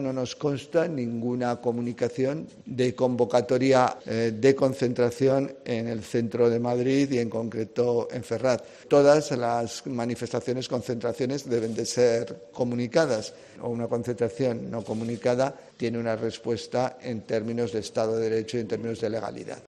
"No nos consta ningún tipo de comunicación", ha señalado Marlaska durante una entrevista en TVE, al ser preguntado por la convocatoria por parte de algunos grupos de agricultores para acceder a la capital con la intención de llegar hasta Ferraz.